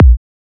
edm-kick-85.wav